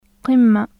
口蓋垂に舌の根元をつけていったん息をせき止めてから，勢いよく開放させ呼気を破裂されて発音する/q/の音です。
無声・口蓋垂・破裂音